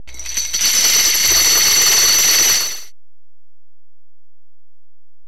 Rattle.wav